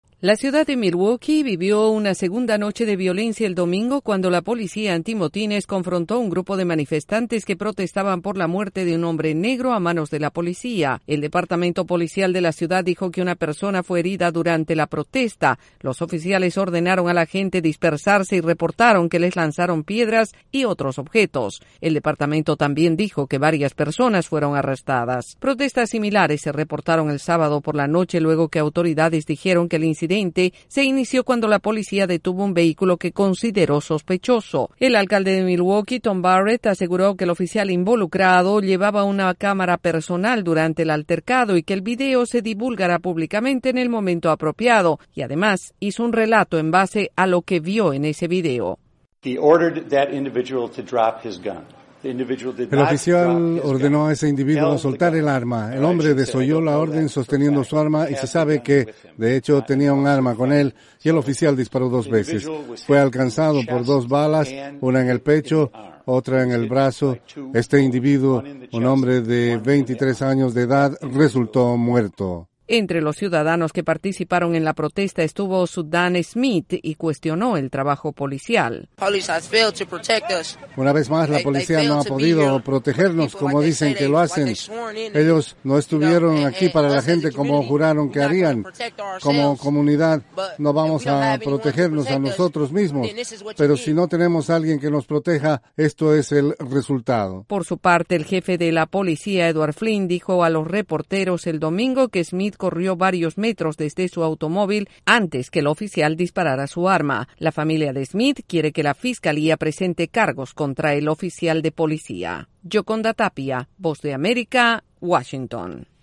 Autoridades en Milwaukee, Wisconsin, hicieron un llamado a la calma luego de los disturbios del fin de semana a raíz de otro incidente en el que murió un afroestadounidense a manos de un policía. Desde la Voz de América en Washington DC informa